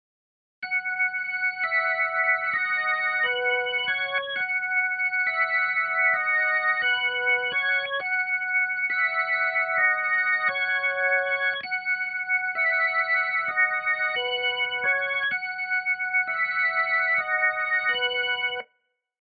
Preset Pipe Organ 2 und etwas am Chorus geschraubt.